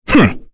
Kain-Vox_Skill7-1_b.wav